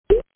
These are the most common ViciDial answering sounds
POP